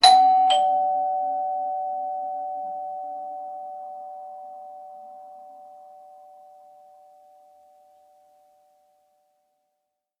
Door Bell
bell bing bong chime ding ding-dong dong door sound effect free sound royalty free Sound Effects